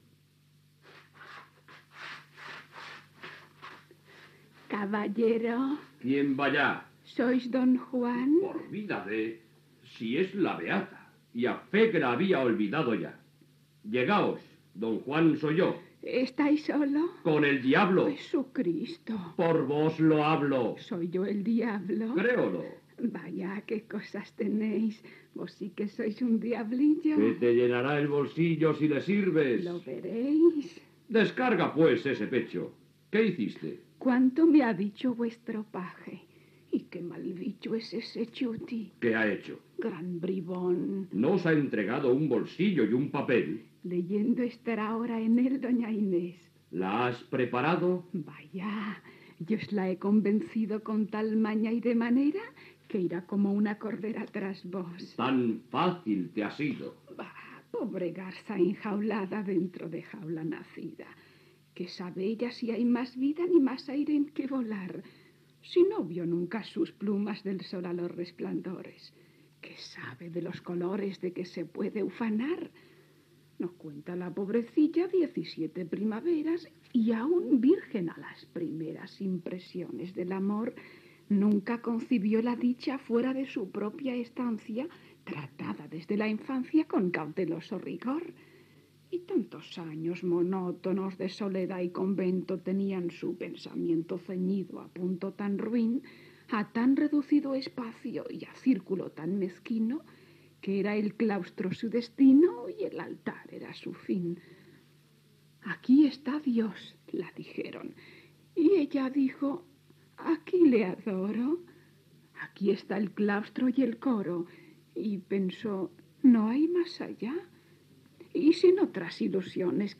Adaptació radiofònica de Don Juan Tenorio de José Zorrilla. Diàleg entre Don Juan i Brigida
Ficció